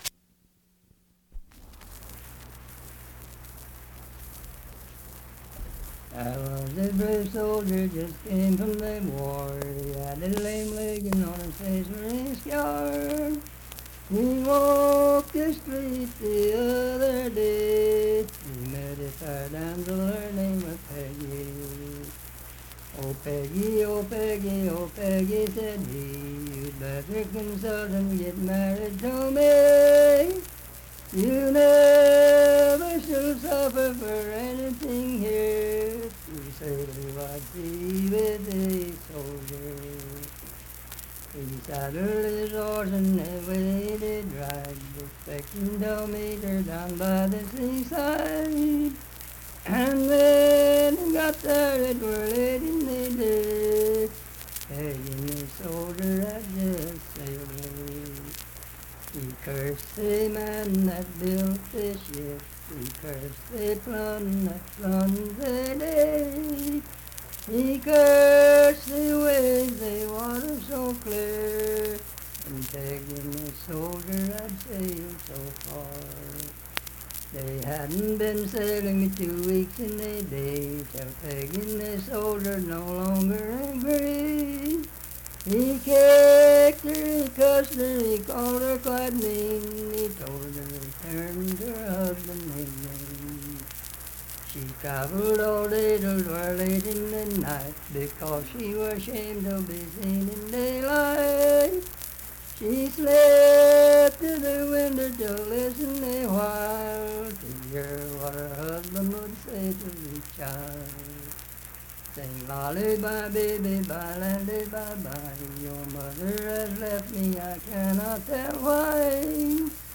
Unaccompanied vocal music
Verse-refrain 7(8).
Voice (sung)
Harts (W. Va.), Lincoln County (W. Va.)